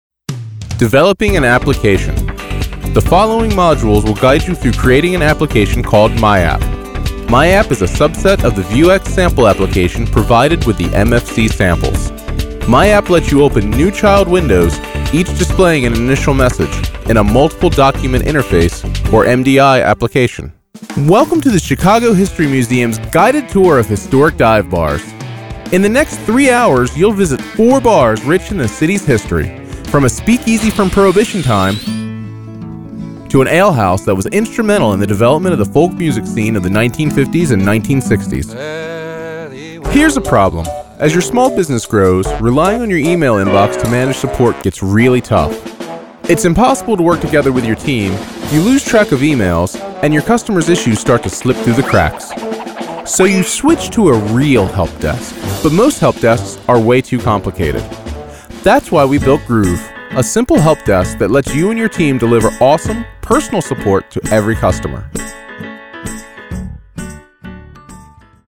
Voiceovers
Having a discrete soundbooth and professional setup, let Modern Eye Media work with you to create high quality productions.
MXL 770 Condenser Microphone, Focusrite Scarlett 2i4 Audio Interface, DBX 286S Preamp/Processor, Adobe Audition Creative Cloud.